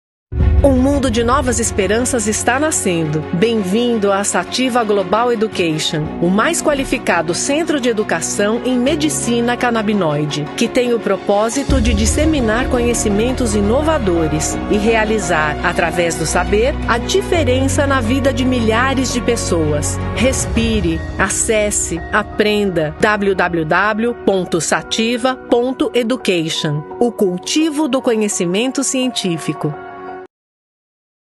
Commercial, Natural, Reliable, Soft, Corporate
Corporate